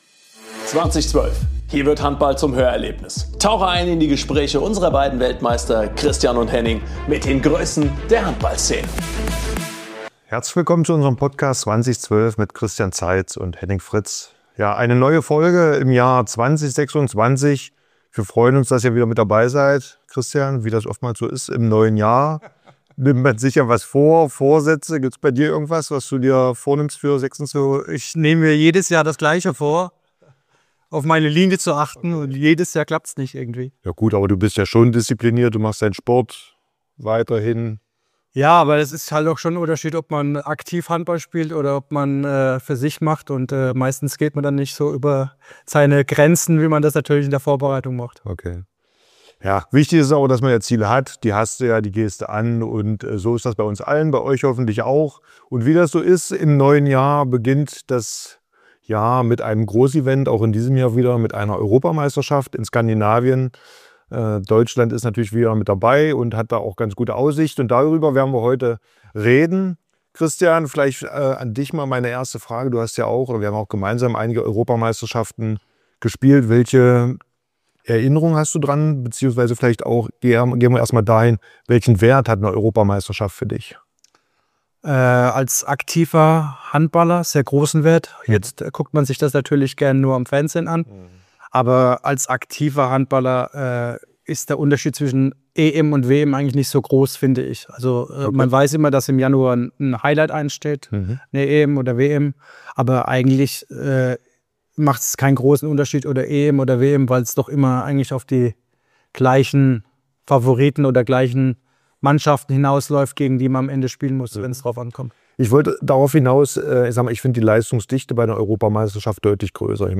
Am Mikrofon: Christian Zeitz & Henning Fritz Zwei Weltmeister.